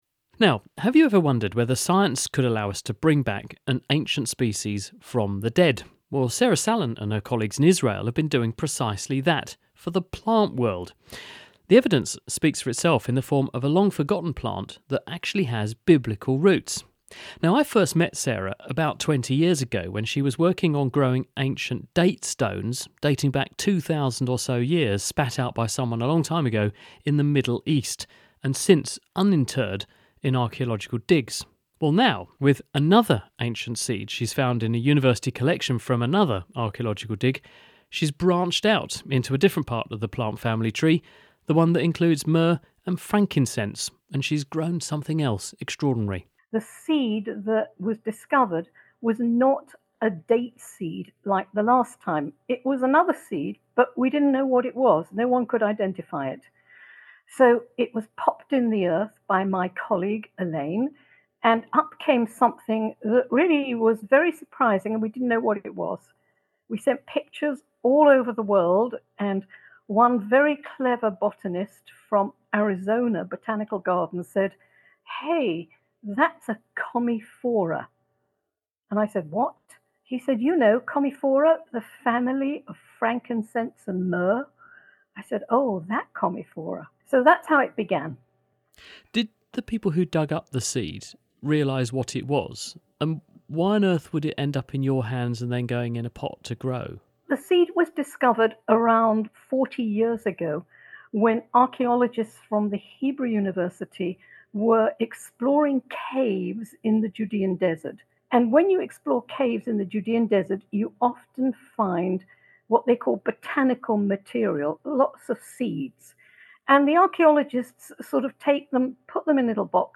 Interviews with Scientists